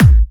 VEC3 Bassdrums Trance 42.wav